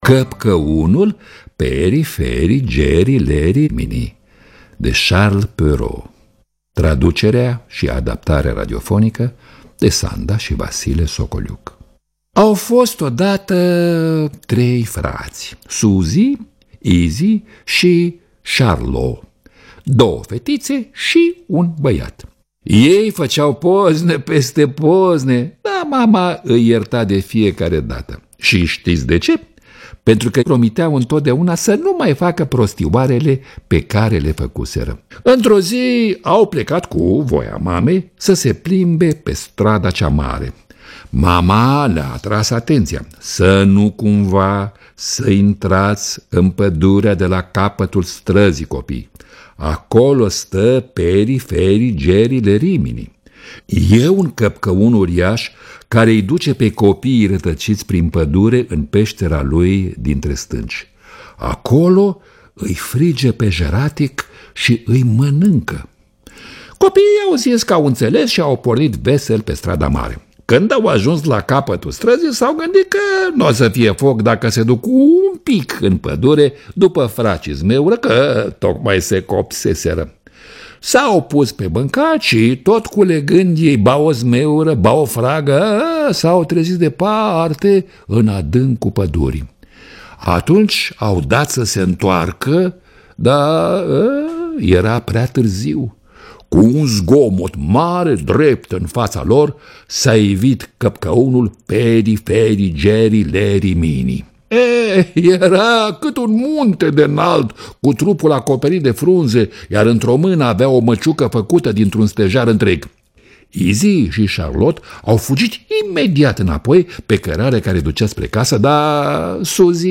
Lectura: Mircea Albulescu.